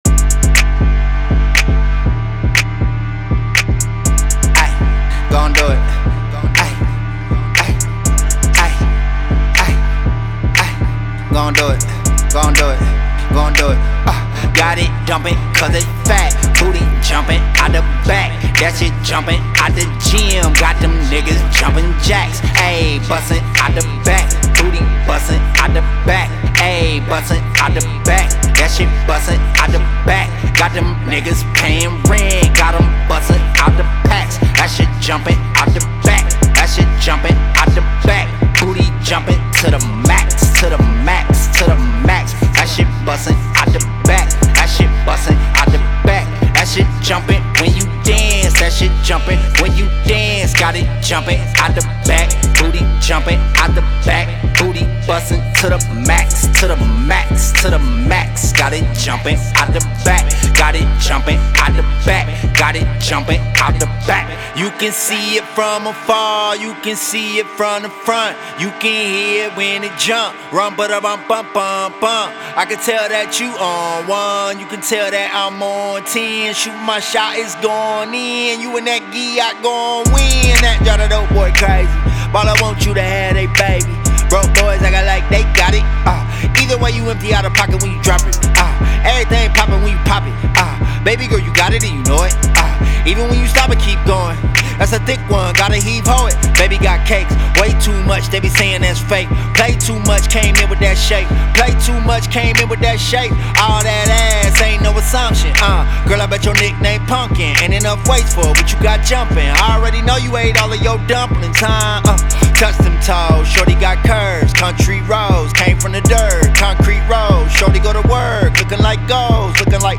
a high-octane hip hop anthem with a dance-floor twist
POSTED IN » hip hop